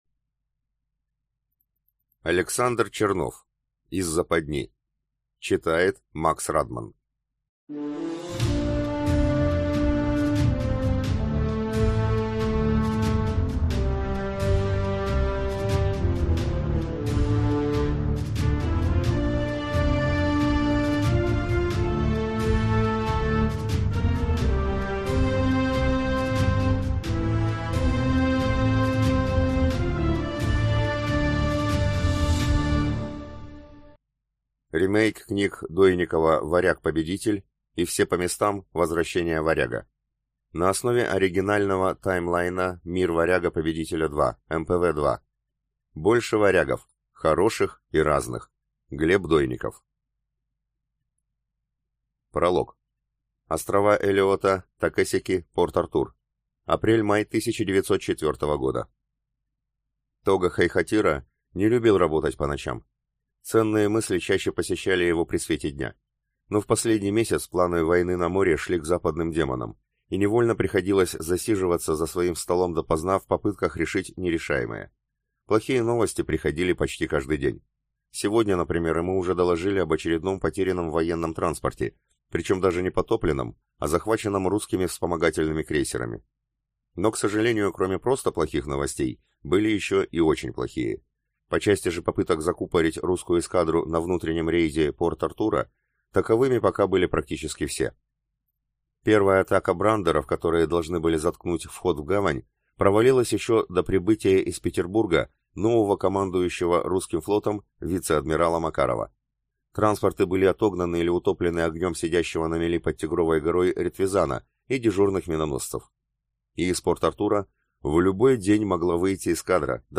Aудиокнига Из западни